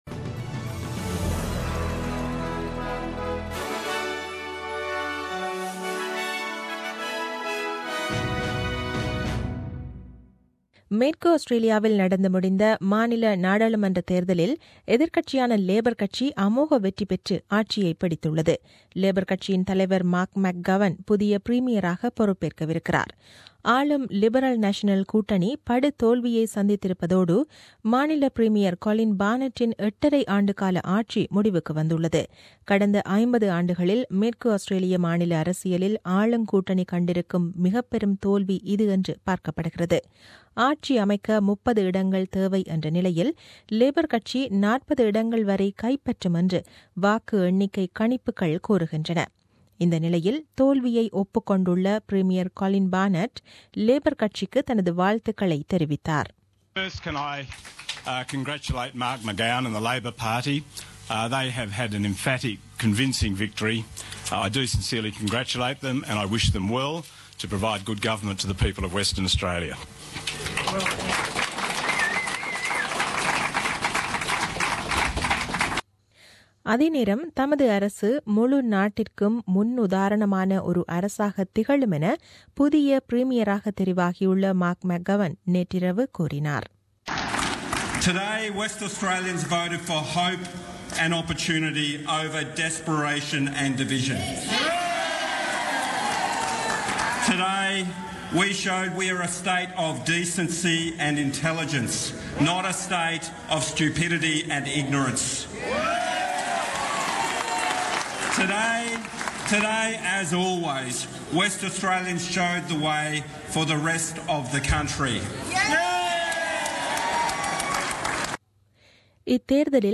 Australian news bulletin aired on 12 Mar 2017 at 8pm.